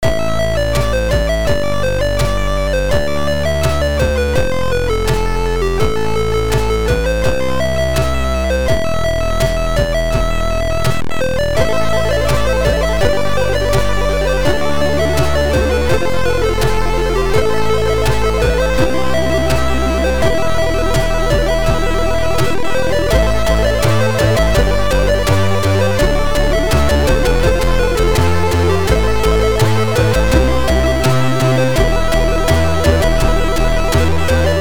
chip